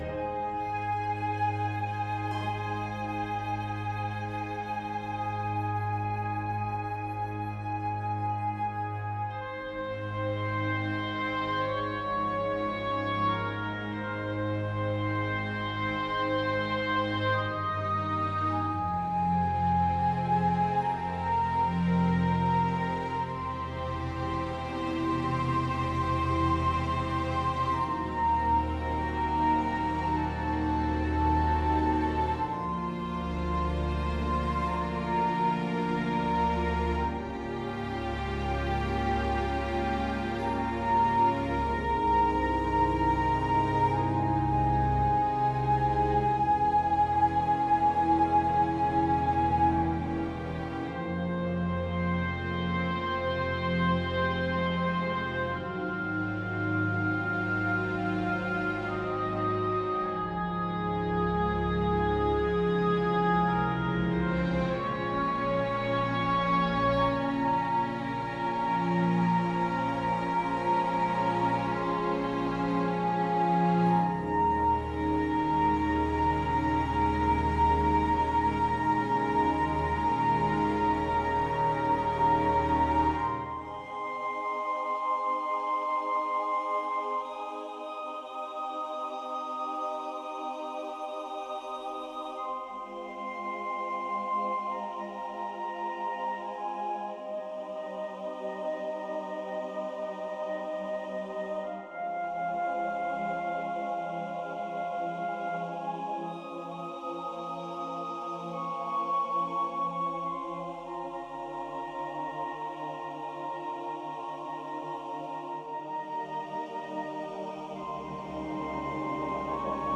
This is a guest post for Silent London by Neil Brand, writer, composer, silent film accompanist and TV and radio presenter.
I have had the privilege of scoring the music to accompany the pre-sound section of this exhibition – I have tried my hardest to come to grips with this epic, ghastly subject through this transformative exhibition and via the particular challenges of tunnel acoustics – it has been a joy and a highly emotional process.